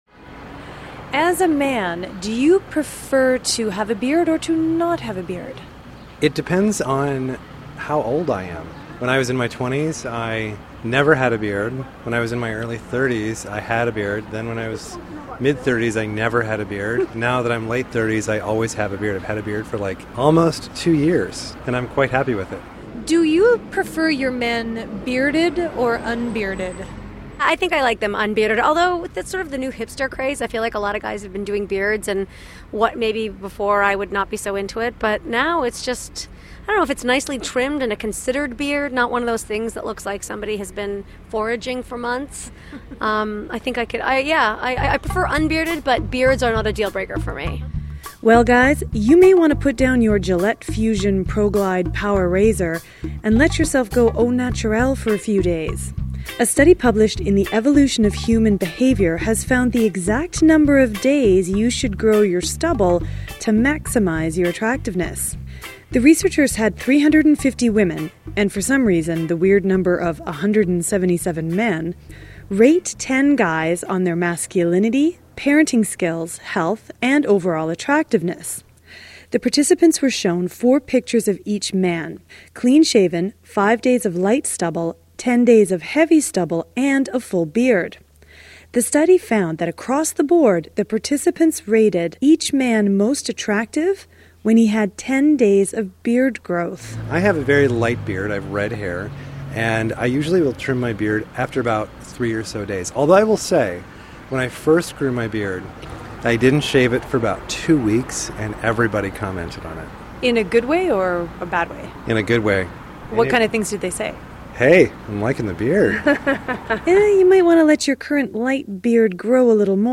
Click on the link below to listen to the story that aired on CBC Radio